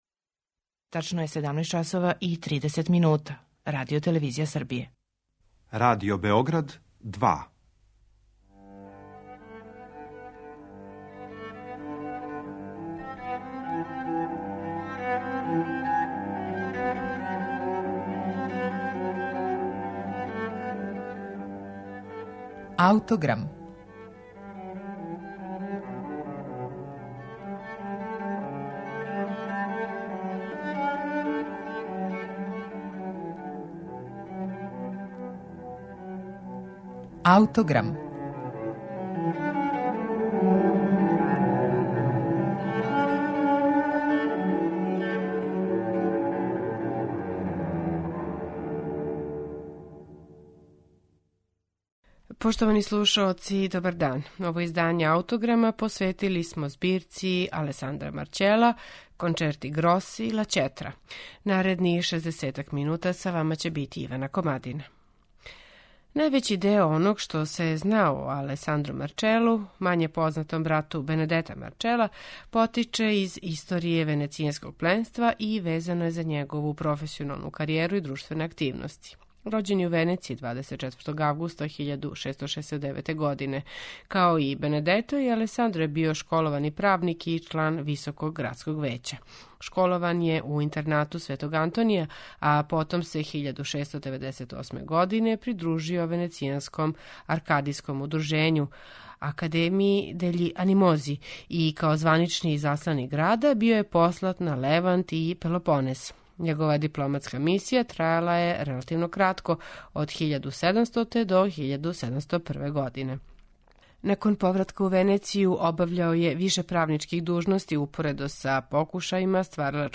Представићемо Марчелову збирку кончерта гроса 'La cetra'.